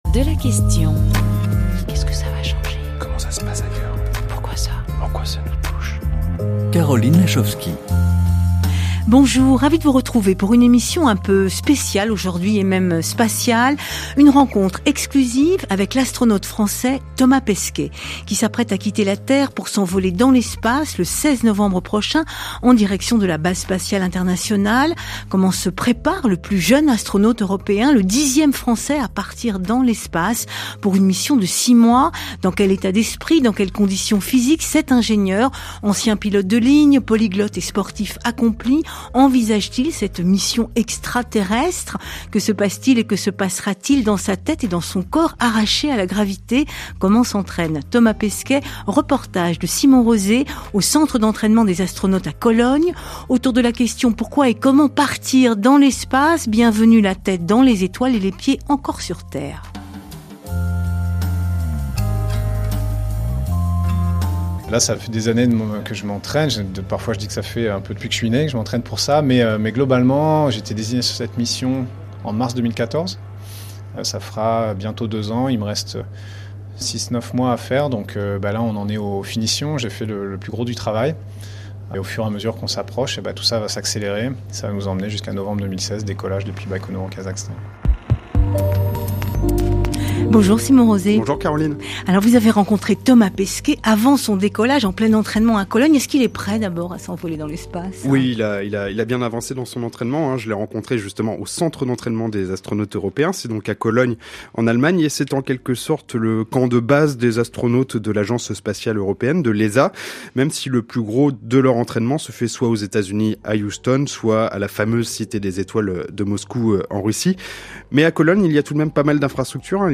– Faire le point sur le vocabulaire de base de l’aérospatial – Se préparer à l’écoute en visionnant le reportage photo 2.